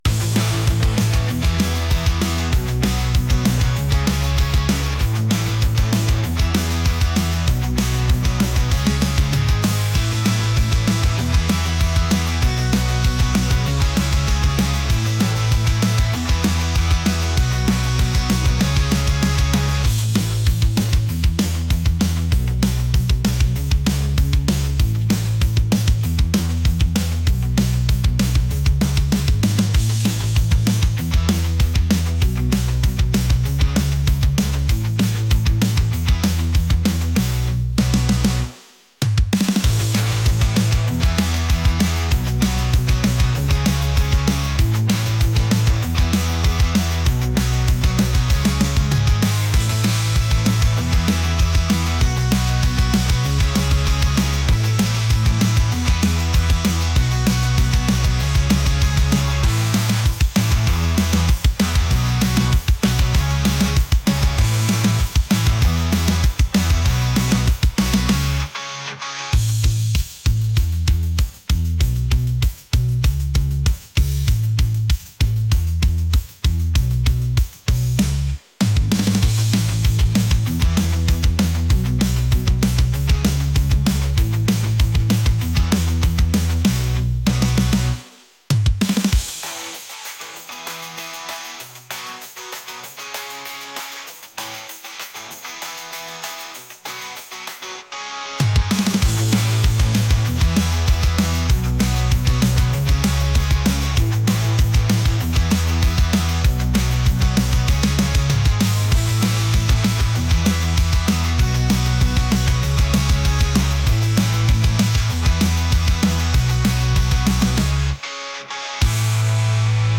energetic | pop | punk